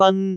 speech
cantonese
syllable
pronunciation